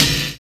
N.Y RAP    2.wav